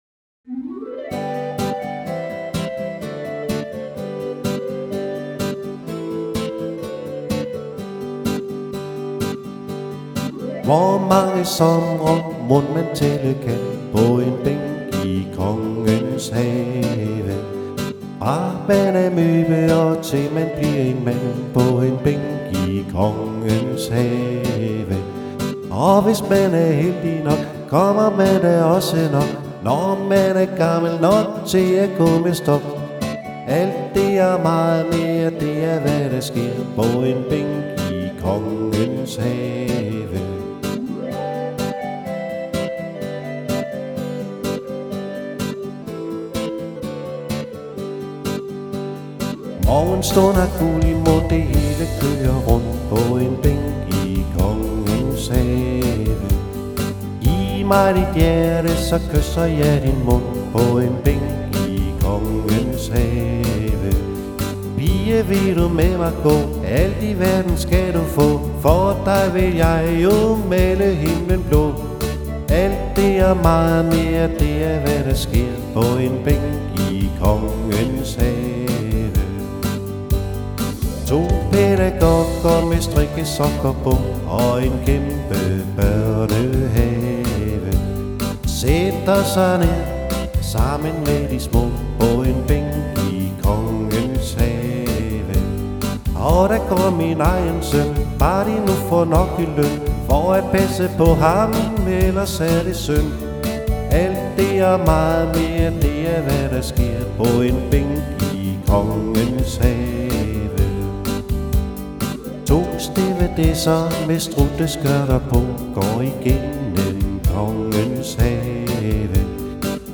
• Solomusiker